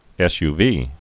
(ĕsyvē)